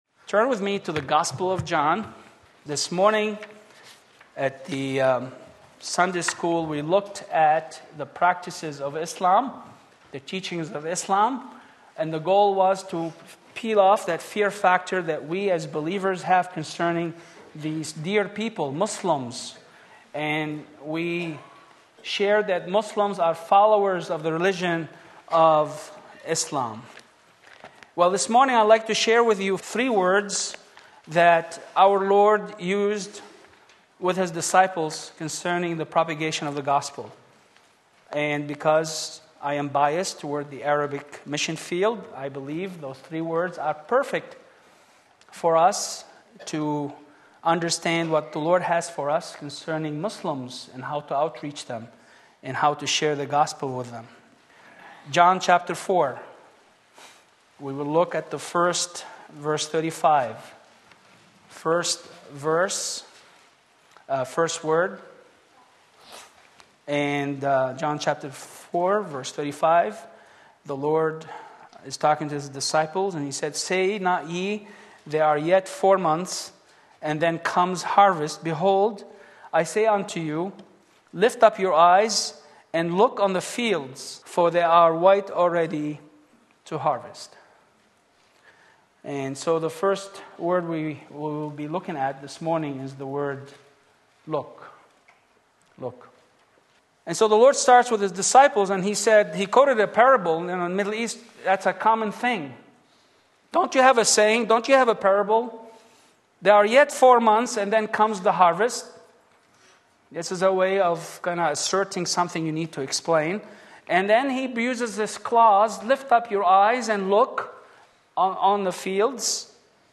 Sermon Link
Matthew 28:18-20 Sunday Morning Service